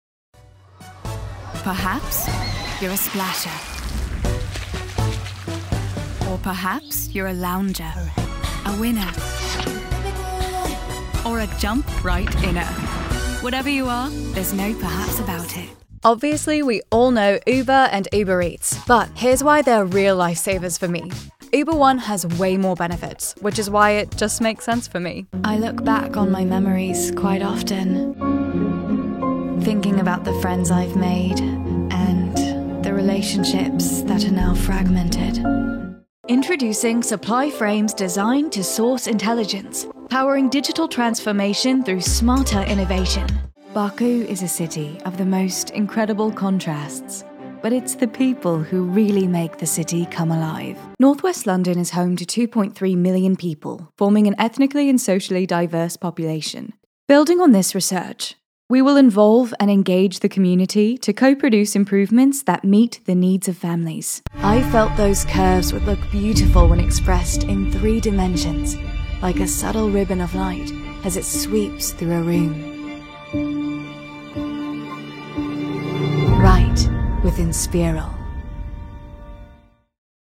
British Reel